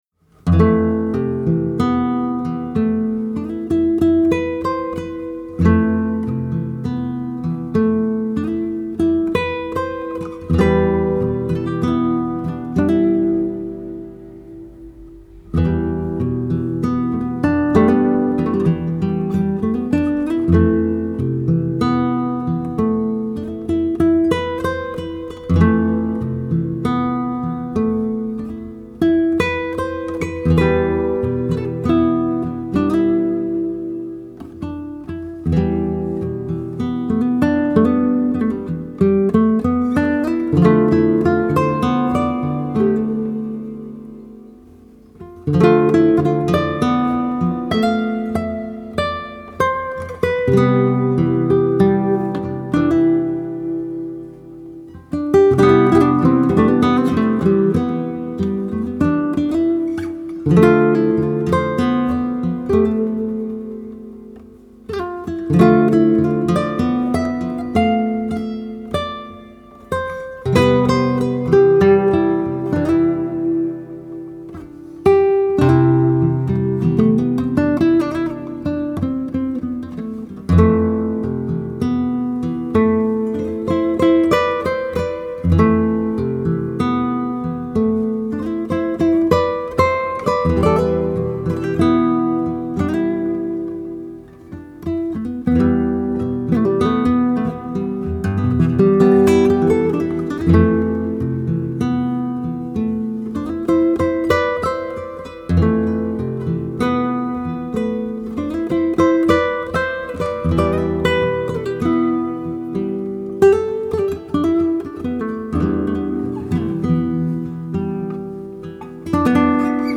موسیقی بی کلام آرامش‌بخش
گیتار
موسیقی بی کلام فولک